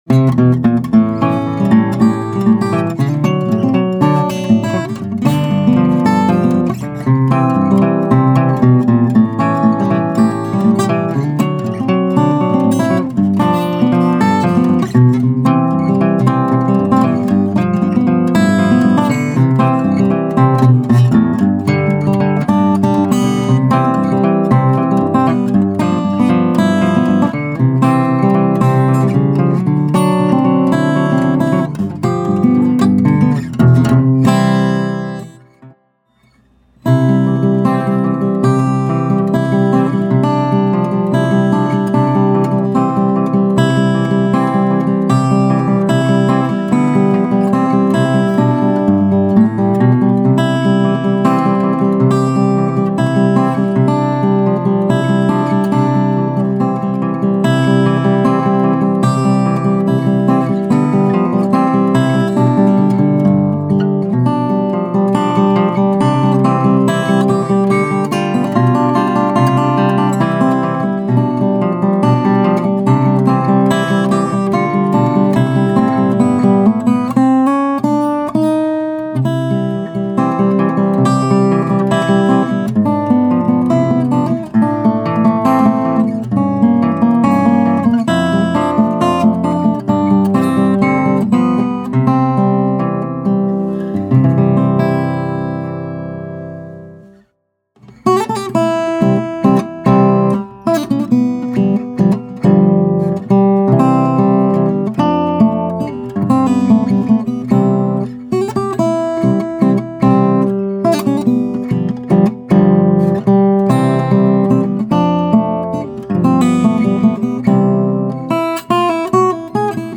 The guitar is quite loud for being so small and the tone very sweet, nice for finger style.